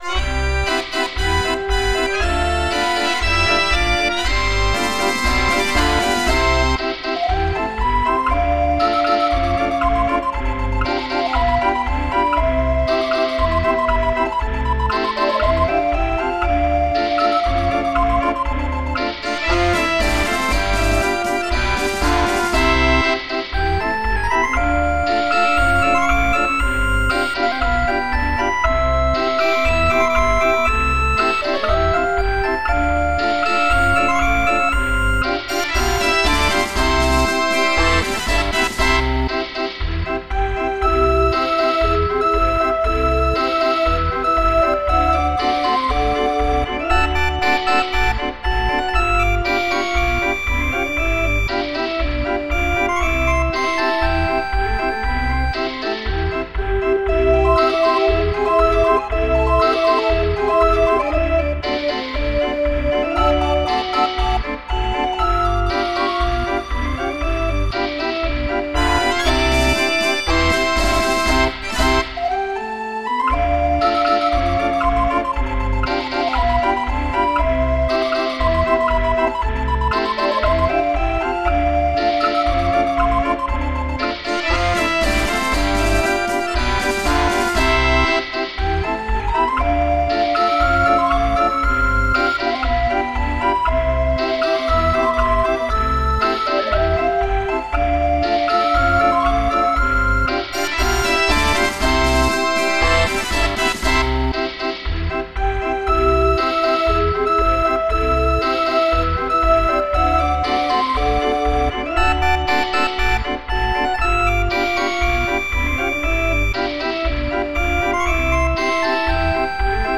Musikrollen, Notenbücher und Zubehör für Drehorgeln.